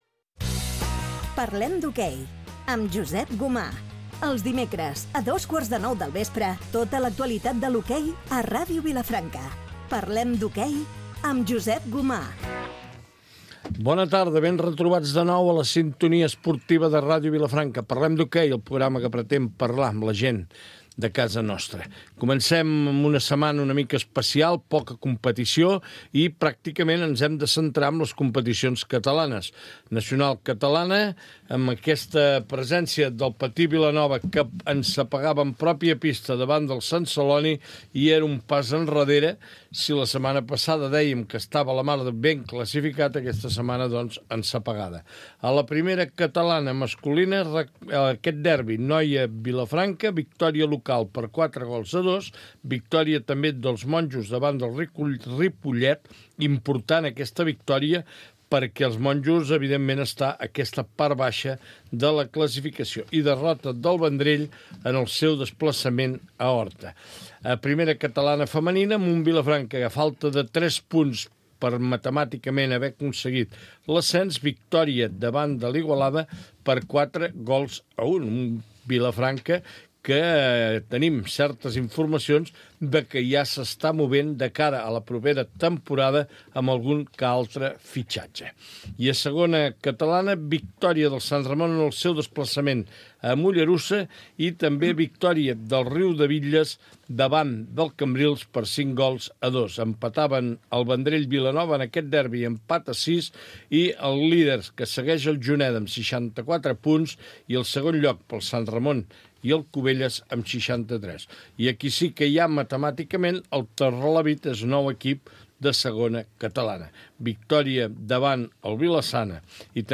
Especial final de temporada des del Casino